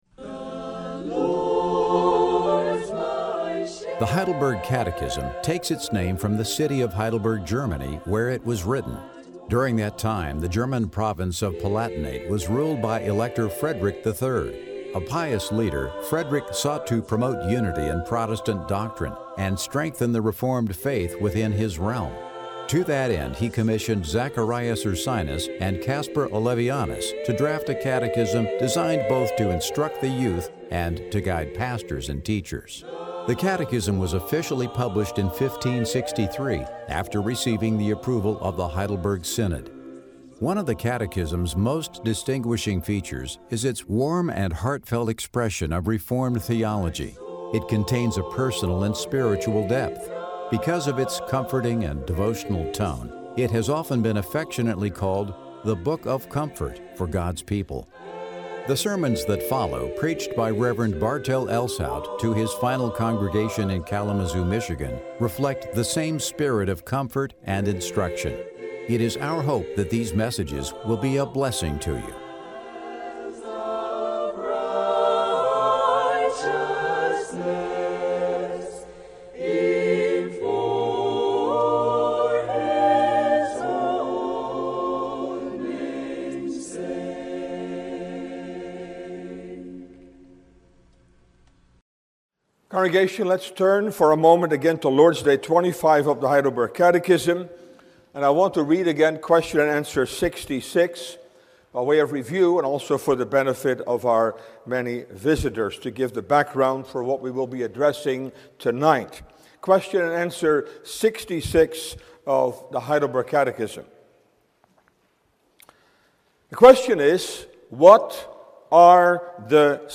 Sermon Downloads